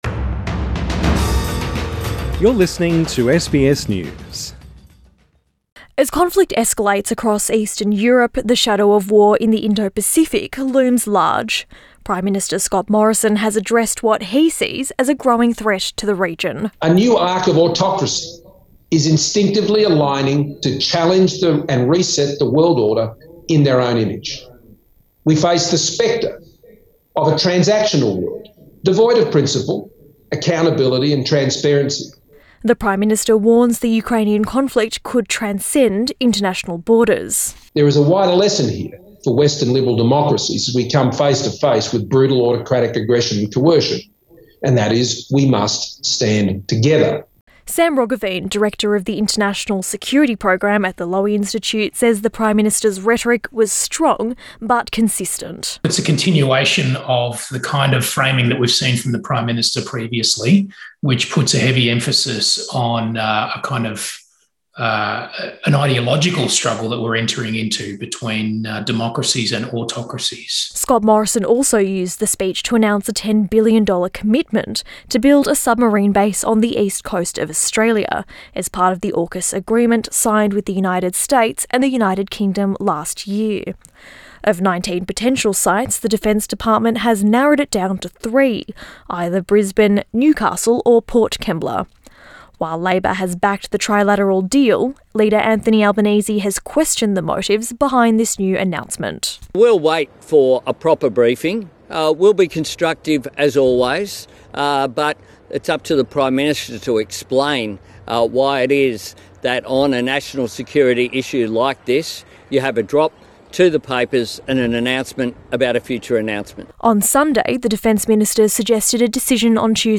Prime Minister Scott Morrison during a virtual address of the Lowy Institute Source: AAP / SUPPLIED/PR IMAGE